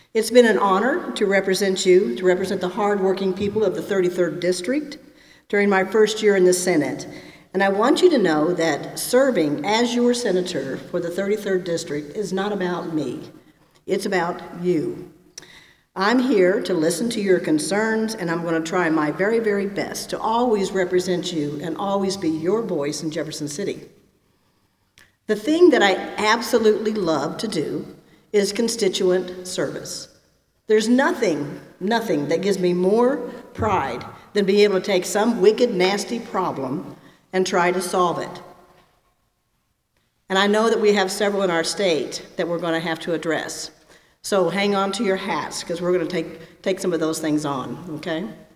The West Plains Chamber of Commerce met Thursday for their regular monthly meeting and the guest speakers were State Representative David Evans and State Senator Karla Eslinger.